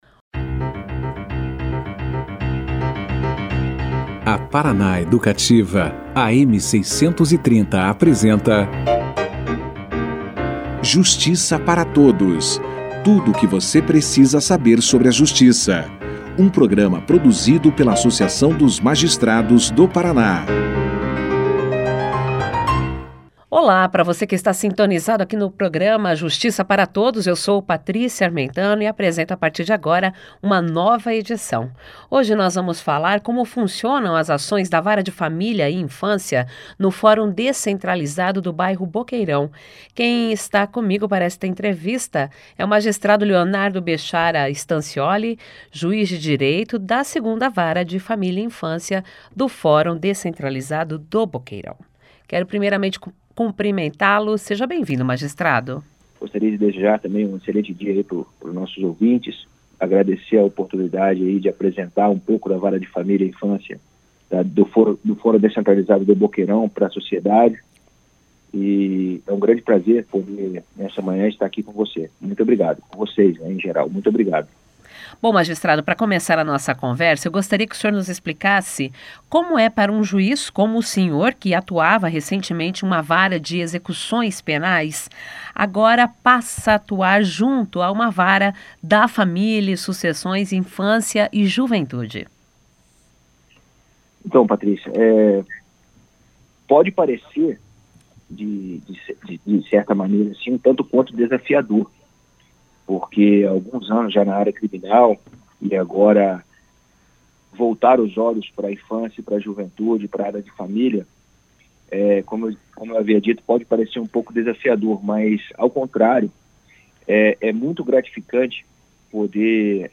O Programa Justiça Para Todos entrevistou o Juiz de direito da 2ª Vara da Família e Sucessões, Infância e Juventude do Fórum Descentralizado do Boqueirão, Leonardo Bechara Stancioli. O magistrado falou sobre as demandas, processos e litígios atendidos com altos índices de conciliações. E apontou os desafios impostos pela pandemia frente a atuação na unidade.